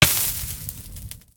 Minecraft Version Minecraft Version snapshot Latest Release | Latest Snapshot snapshot / assets / minecraft / sounds / entity / player / hurt / fire_hurt3.ogg Compare With Compare With Latest Release | Latest Snapshot
fire_hurt3.ogg